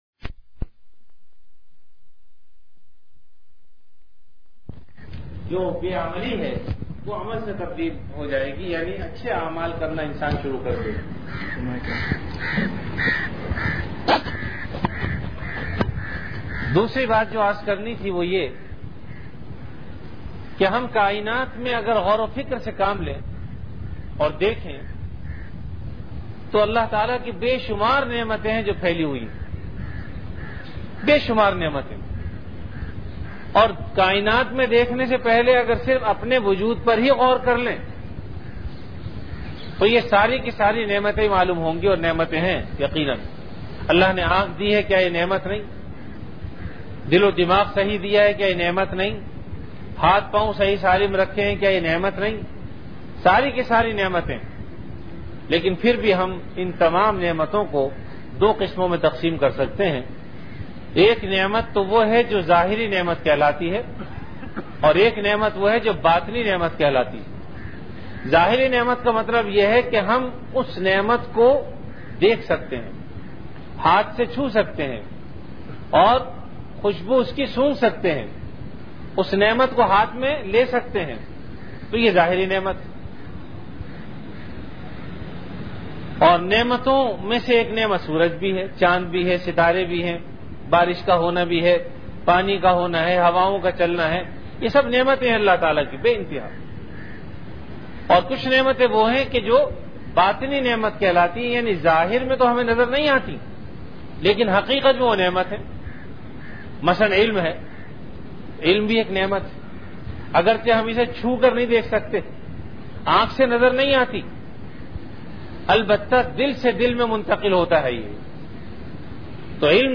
Bayanat · Jamia Masjid Bait-ul-Mukkaram, Karachi
Event / Time After Isha Prayer